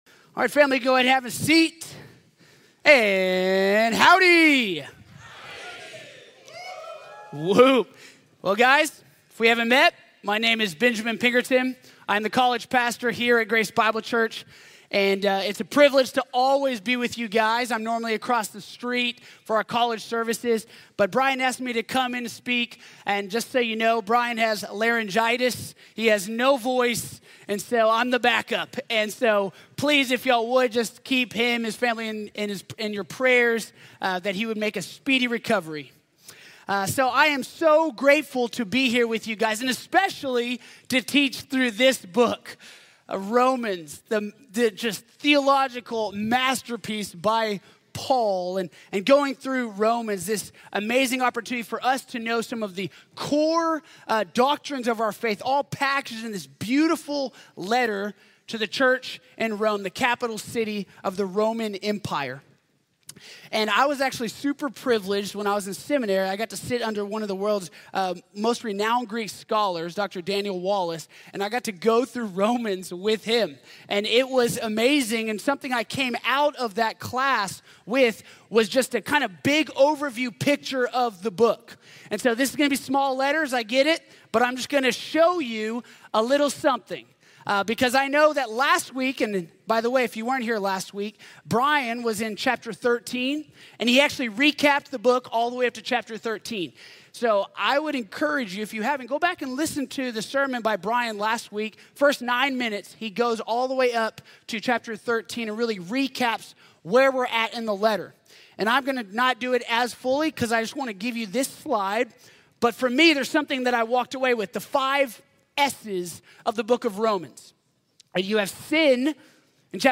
When the Gospel Meets Our Grey Areas | Sermon | Grace Bible Church